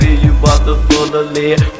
FULLA LEAD.wav